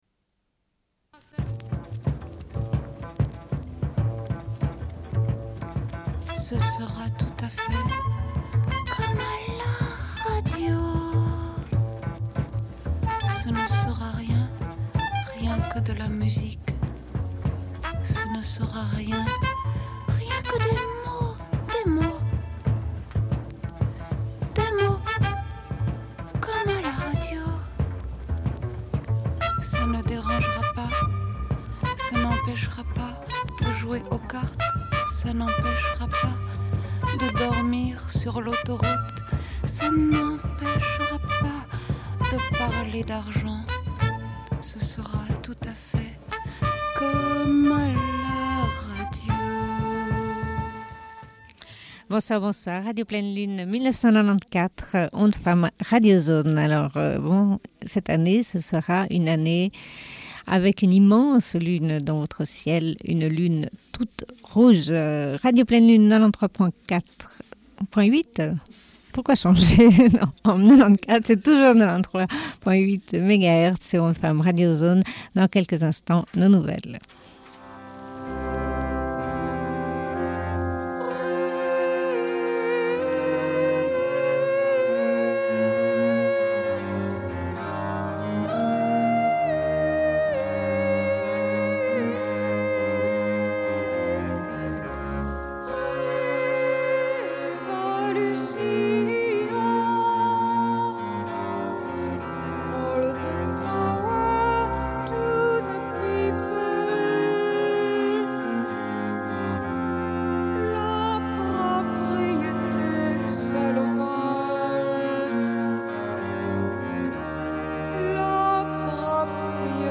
Bulletin d'information de Radio Pleine Lune du 12.01.1994 - Archives contestataires
Une cassette audio, face B29:08